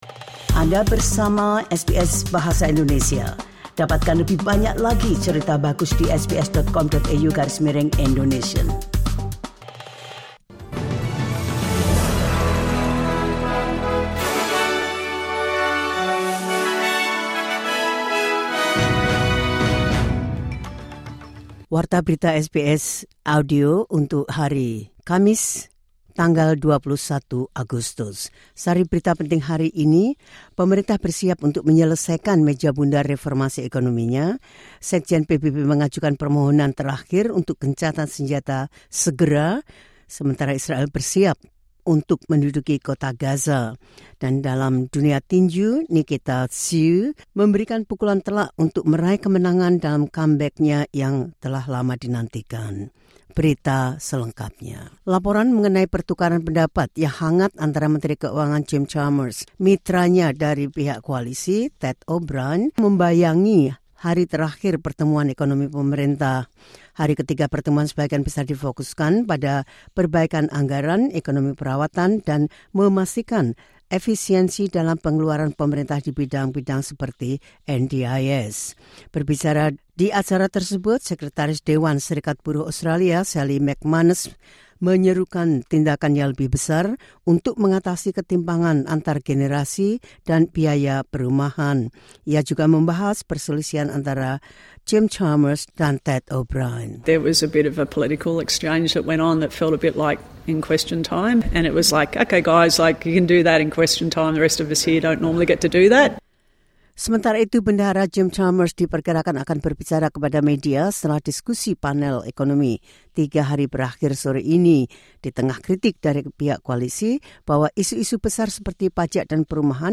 The latest news SBS Audio Indonesian Program – 21 August 2025.